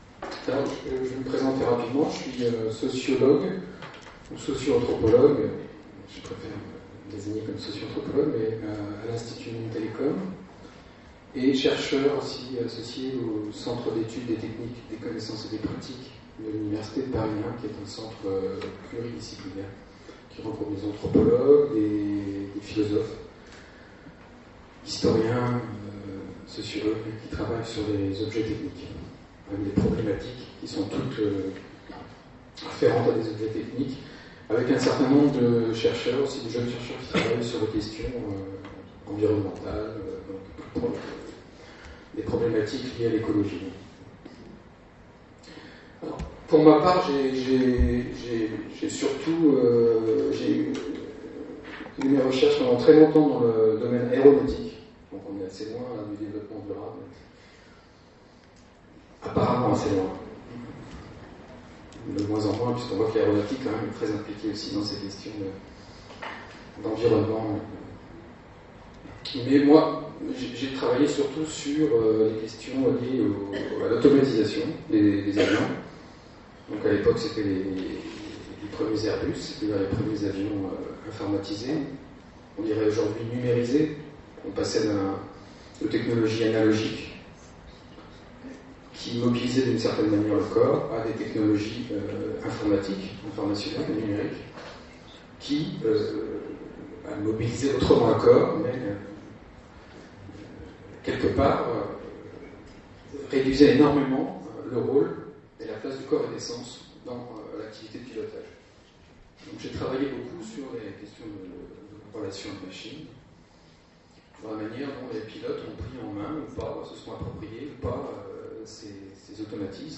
Troisième journée de l’atelier Développement durable et “acceptabilité sociale”